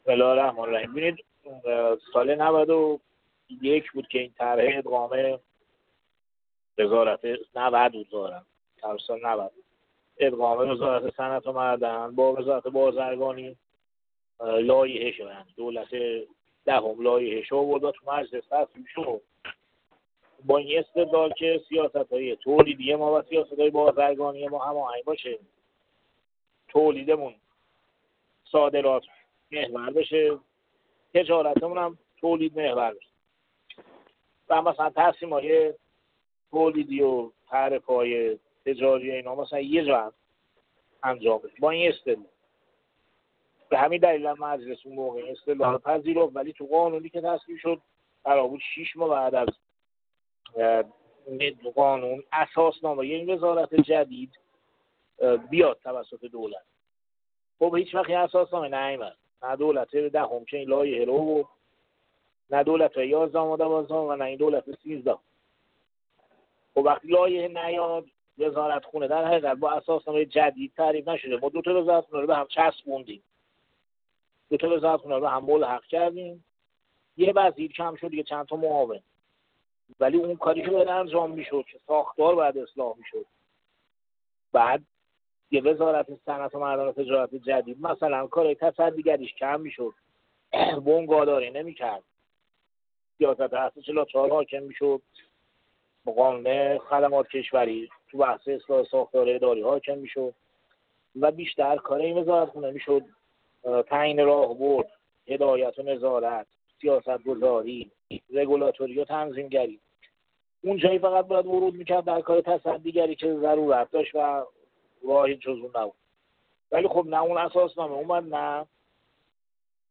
فولادگر در گفت‌وگو با ایکنا: